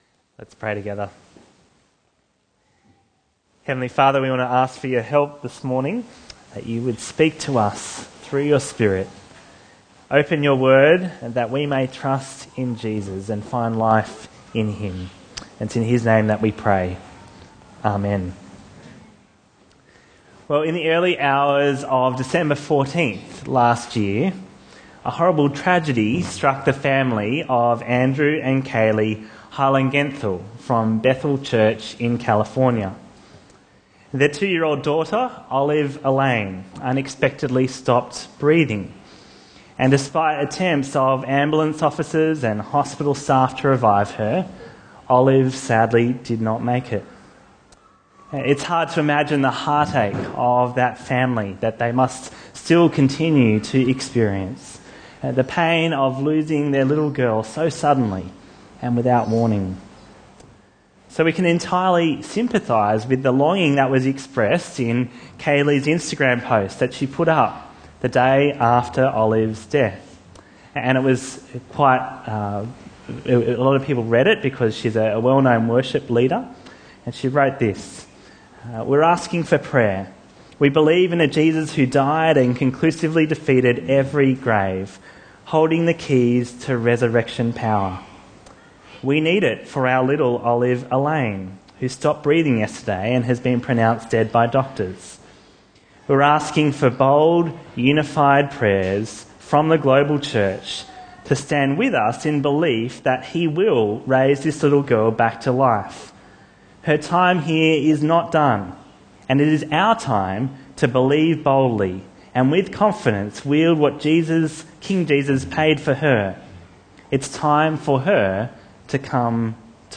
Bible Talks Bible Reading: Matthew 9:18-36